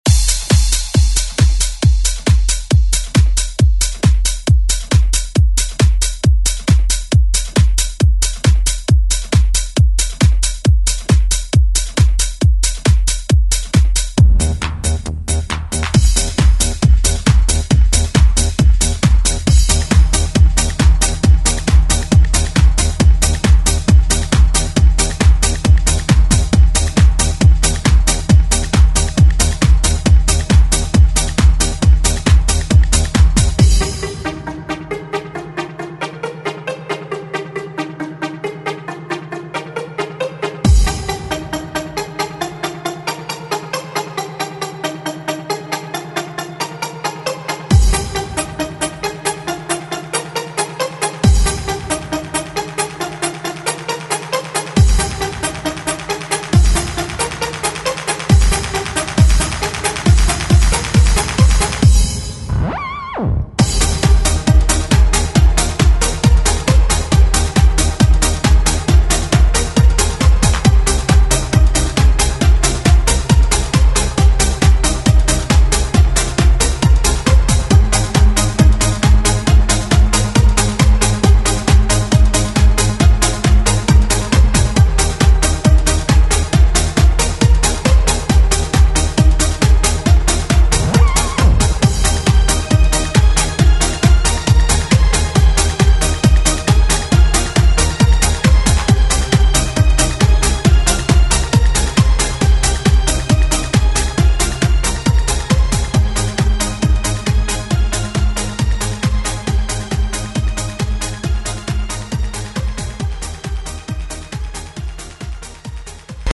Throwback Hip Hop Rap RnB Music
Extended Intro Outro
97 bpm